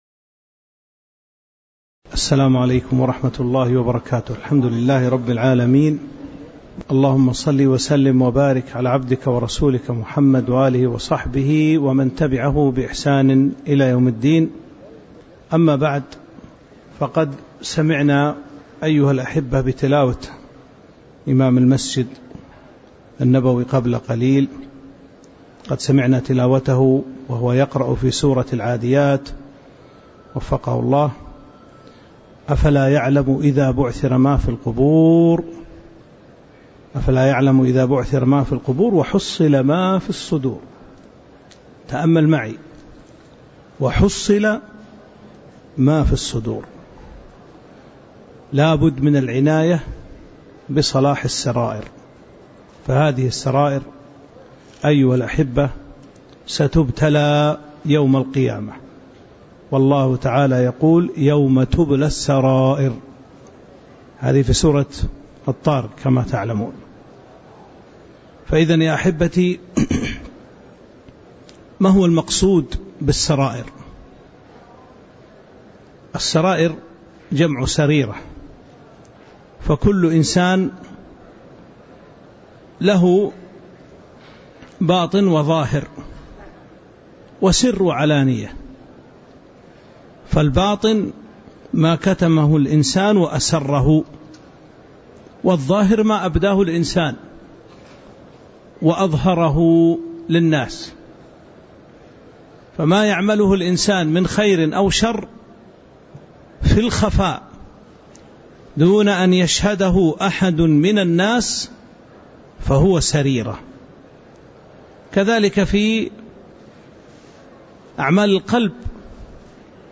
تاريخ النشر ١٤ ذو القعدة ١٤٤٥ هـ المكان: المسجد النبوي الشيخ